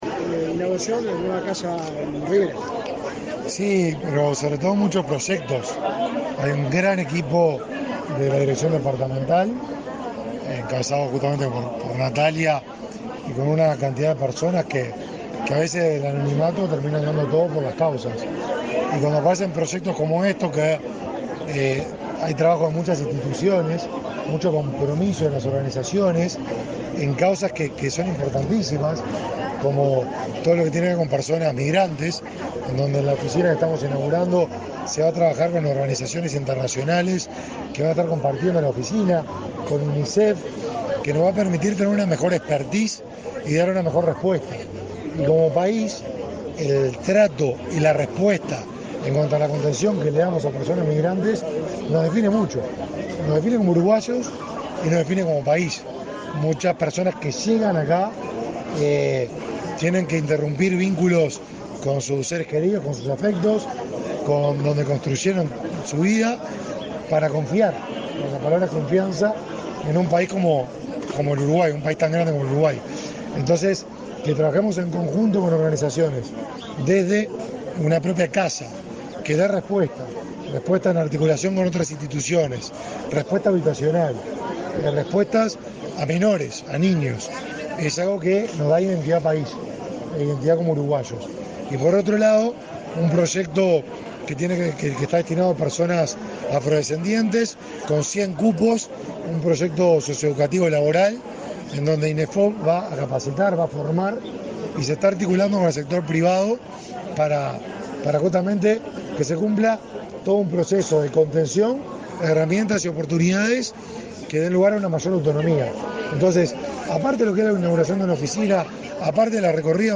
Declaraciones a la prensa del ministro de Desarrollo Social (Mides), Martín Lema
Declaraciones a la prensa del ministro de Desarrollo Social (Mides), Martín Lema 22/03/2023 Compartir Facebook X Copiar enlace WhatsApp LinkedIn Tras participar en la inauguración de la oficina territorial de Rivera del Mides, este 22 de marzo, el ministro Martín Lema realizó declaraciones a la prensa.